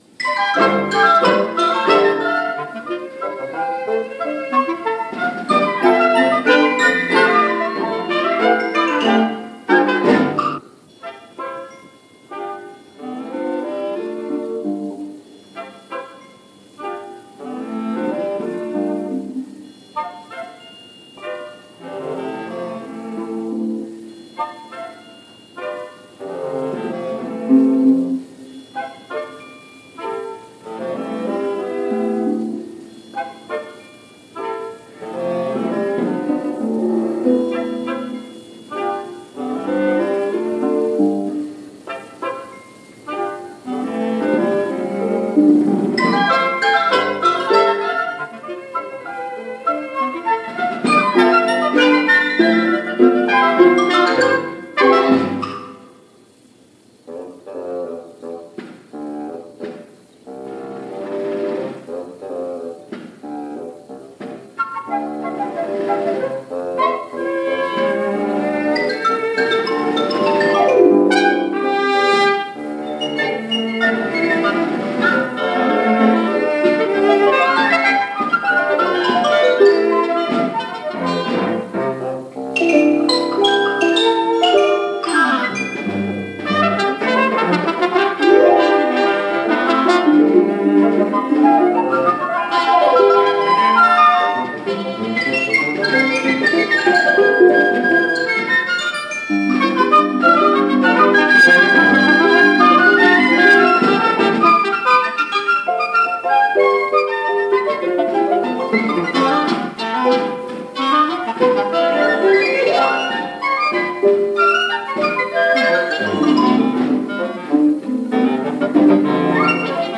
Harp
Broadcast Studio performance